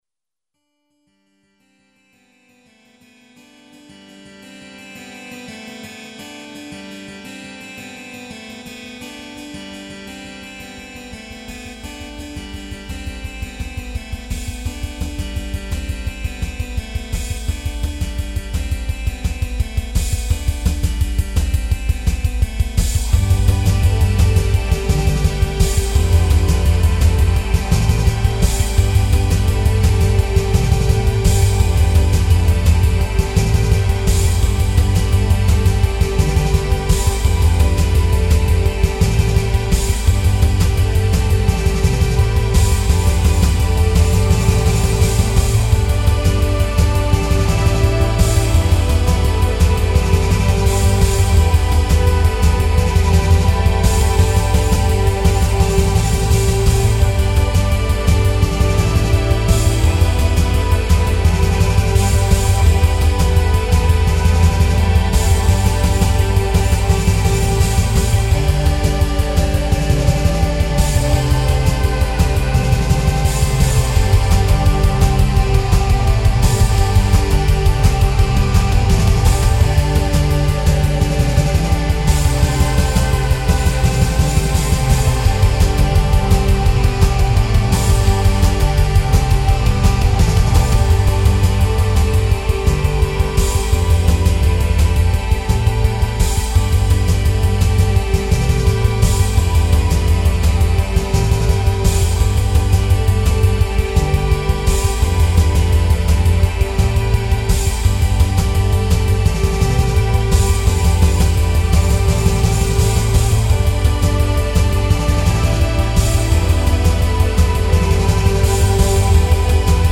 Melodic Rock
'05 Remix EQ - Live Guitar